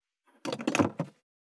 215,机に物を置く,テーブル等に物を置く,食器,グラス,コップ,工具,小物,雑貨,コトン,
コップ